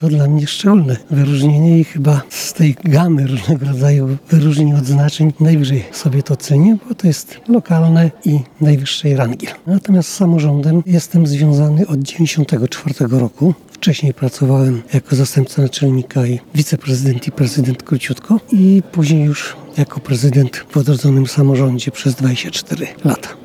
Były wieloletni prezydent Puław Janusz Grobel został honorowym obywatelem tego miasta. Wyróżnienie zostało wręczone w Domu Chemika podczas gali 35-lecia samorządu terytorialnego.